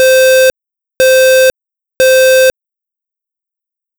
- Add voice siren audio files 2024-08-11 20:41:39 -06:00 345 KiB Raw History
FireSiren.wav